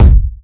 Tuned drums (F key) Free sound effects and audio clips
• Round Kick Drum One Shot F Key 162.wav
Royality free kick drum sound tuned to the F note. Loudest frequency: 145Hz
round-kick-drum-one-shot-f-key-162-i2W.wav